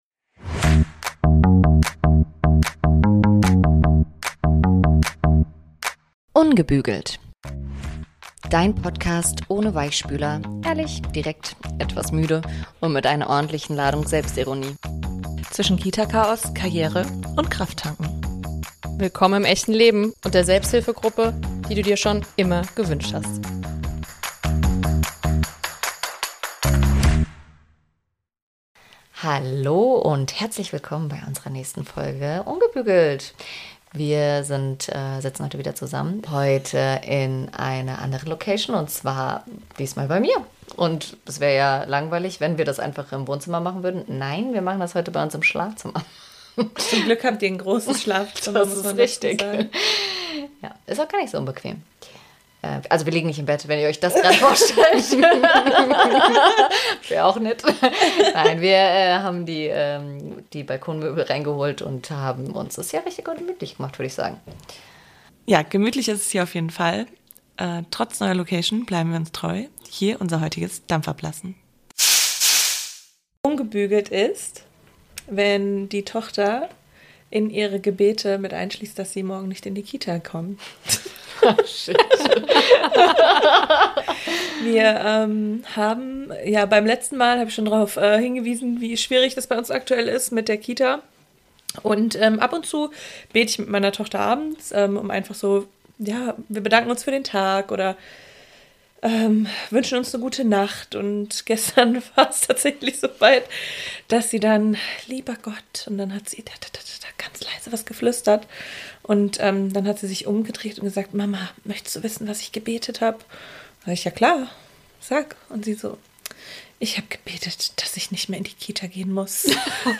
In der heutigen Folge sitzen wir in entspannter Schlafzimmer Atmosphäre zusammen, um über die Organisation des Familienalltags, Teilzeitarbeit und die damit verbundenen Schwierigkeiten, insbesondere die des "Teilzeit-Muddi"-Stigmas, zu sprechen.